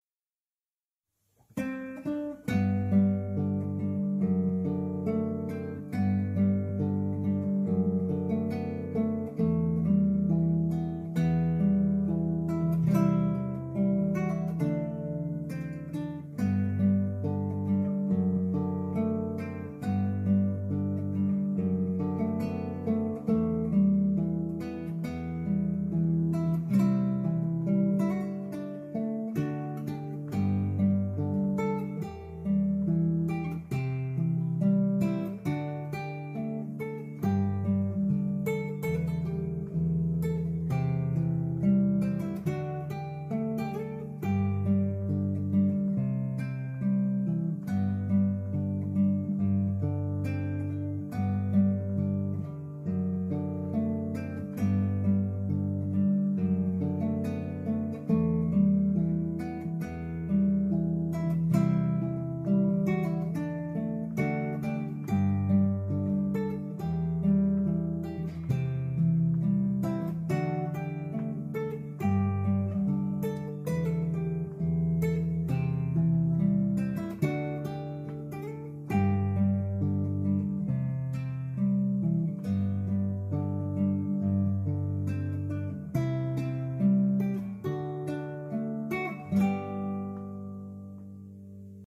Audio Clip from the Tutorial
Standard Tuning - 4/4 Time